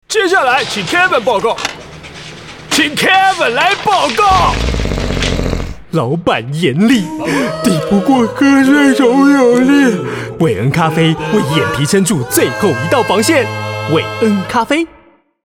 國語配音 男性配音員